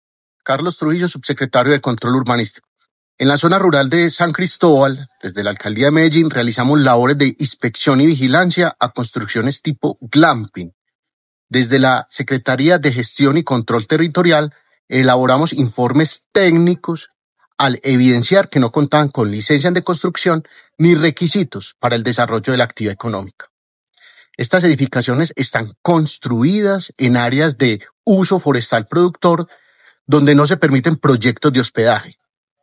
Declaraciones del subsecretario de Control Urbanístico, Carlos Trujillo
Declaraciones-del-subsecretario-de-Control-Urbanistico-Carlos-Trujillo.mp3